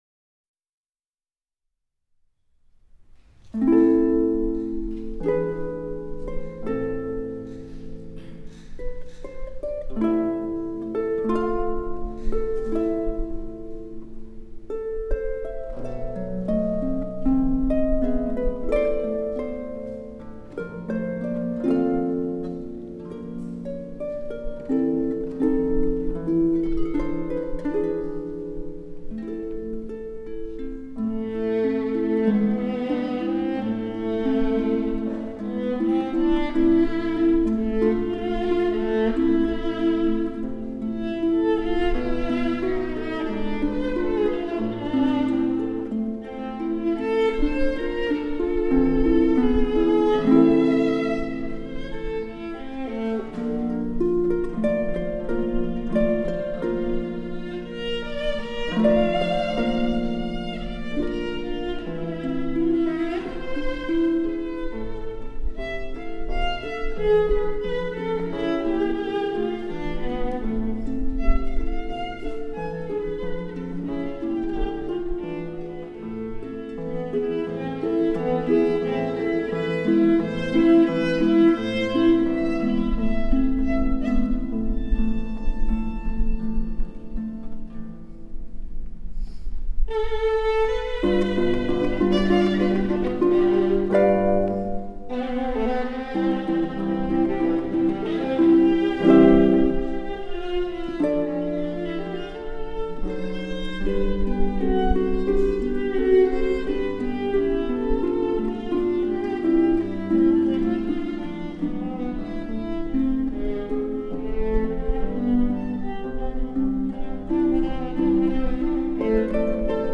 Violin
Harp